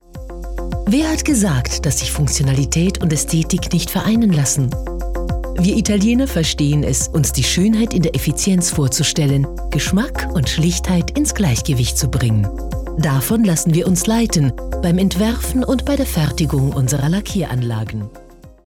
Meistgebuchte Schauspielerin mit breitem Einsatzspektrum. Diverse Dialekte und Trickstimmen.